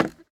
Minecraft Version Minecraft Version snapshot Latest Release | Latest Snapshot snapshot / assets / minecraft / sounds / block / bamboo / step5.ogg Compare With Compare With Latest Release | Latest Snapshot
step5.ogg